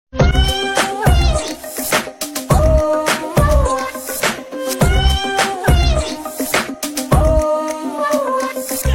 wiii wiwi uuuu wawa
kitty cat
u3-wiii-wiwi-uuuu-wawa.mp3